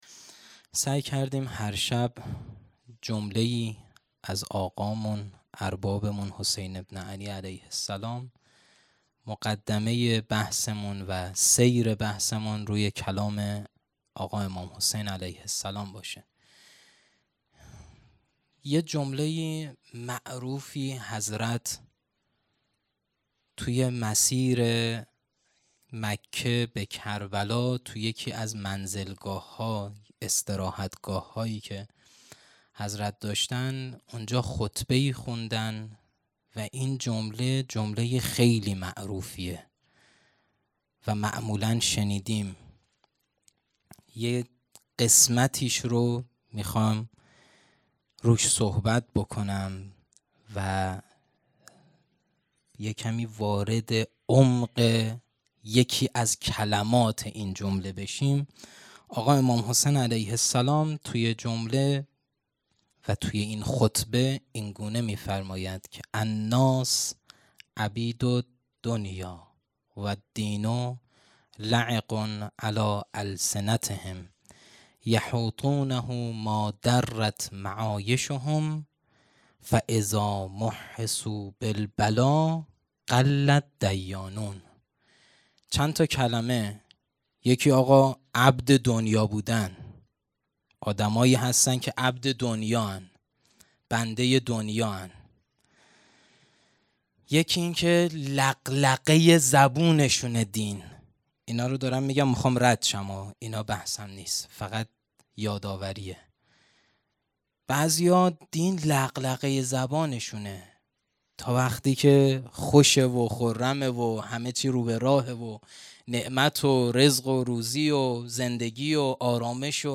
محرم 1440 _ شب دهم